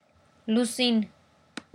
Armenian Eastern[18] լուսին/lusin
[lusin] 'moon'